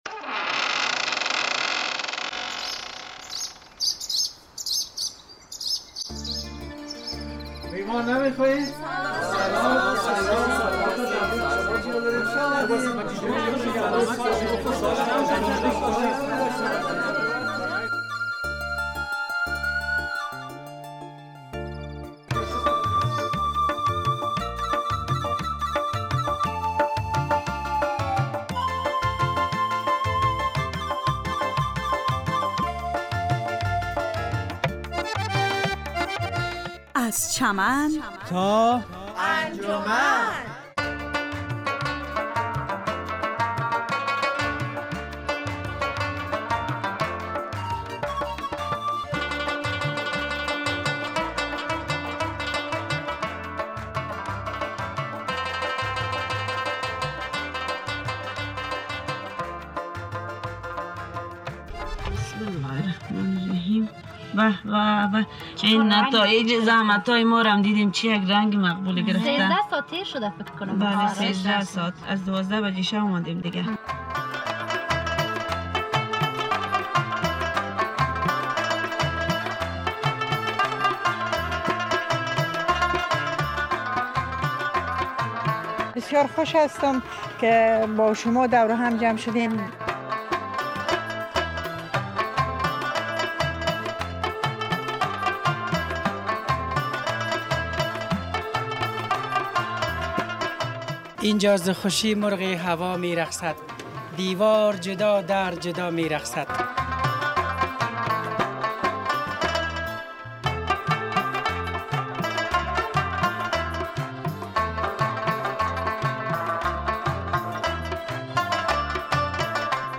از چمن تا انجمن- مستند میله و جشن سمنک یا همان سمنو در افغانستان